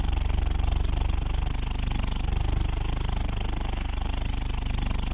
sum_airship_lip_trill.ogg